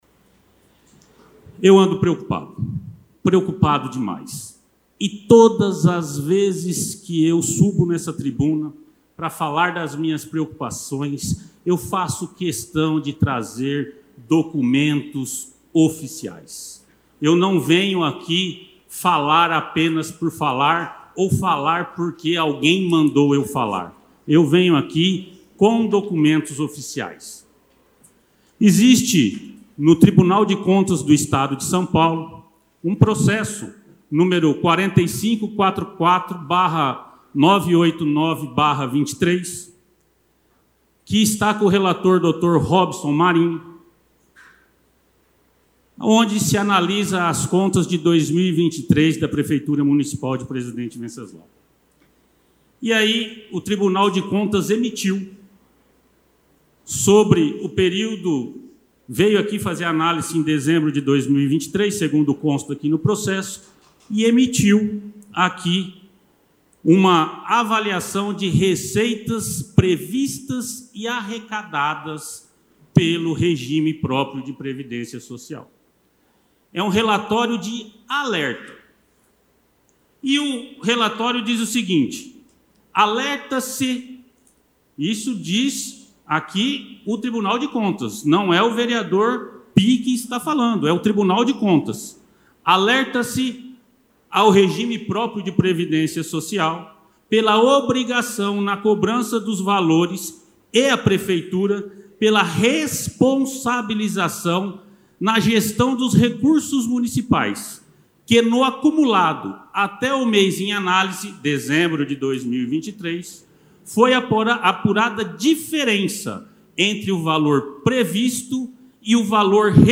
Vereador Tácito Alexandre na Sessão de ontem na Câmara Municipal.
TACITO-ALEXANDRE-fala-do-ipreven.mp3